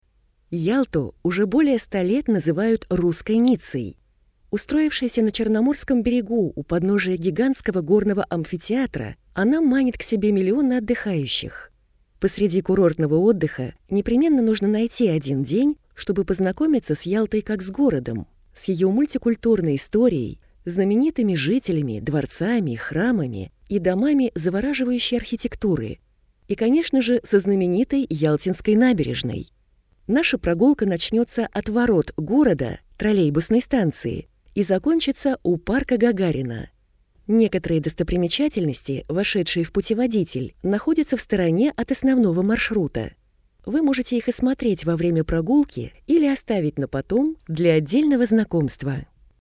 Аудиокнига Ялта. Аудиогид | Библиотека аудиокниг